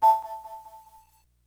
beep-notify.wav